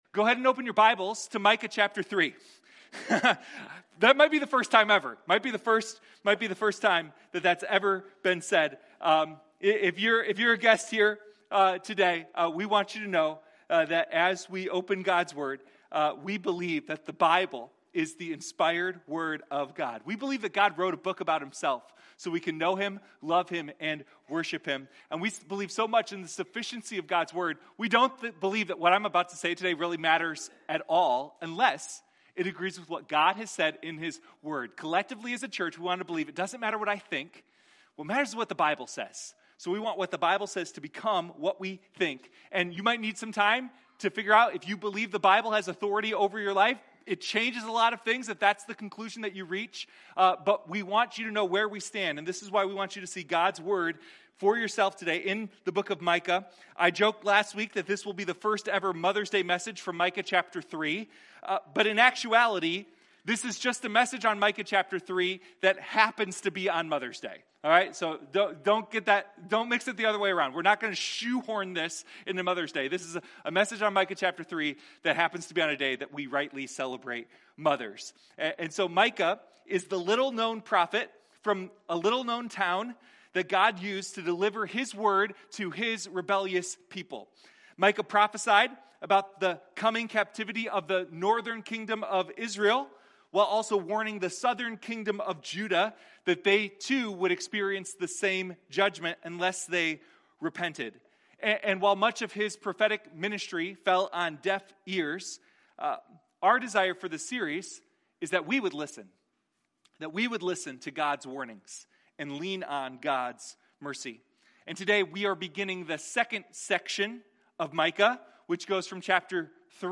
Sunday Morning Micah: Who is like the Lord?